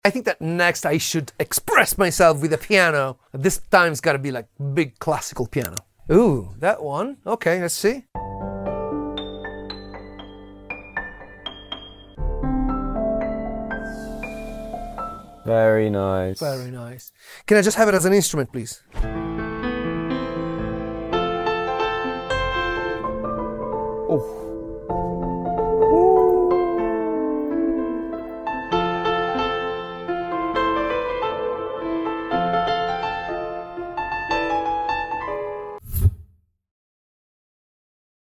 the luscious, beautiful, and emotional sound of Virtual Pianist SCORE